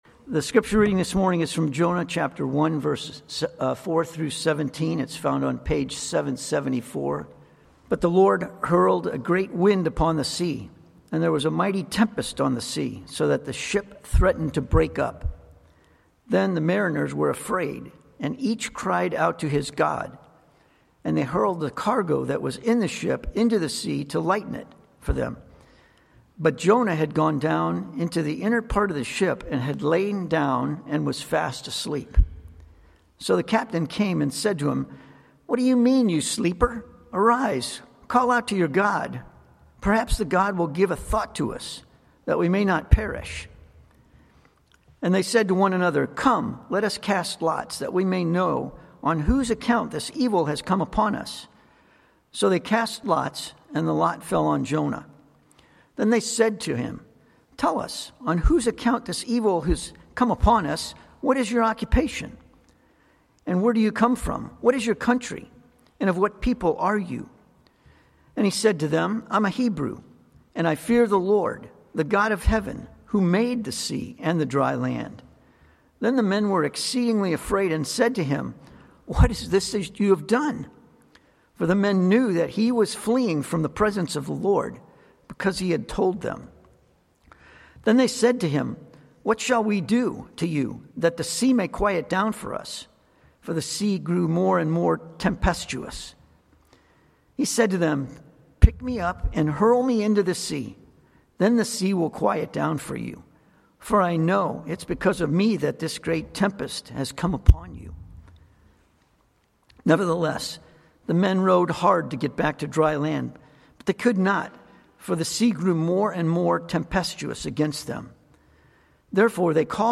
Passage: Jonah 1:4-17 Sermon